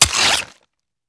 swipe1.wav